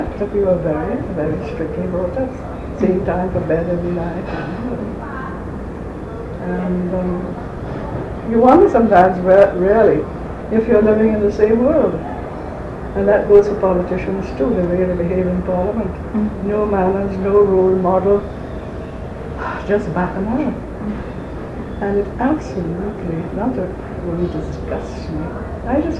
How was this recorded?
1 audio cassette